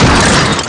CAR3.WAV